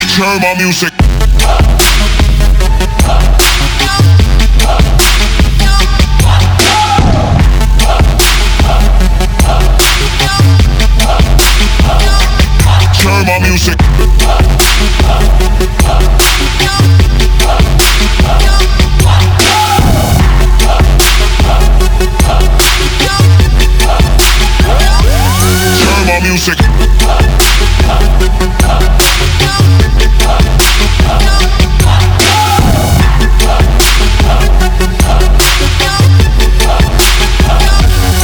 • Качество: 320, Stereo
громкие
Trap
басы
качающие
Bass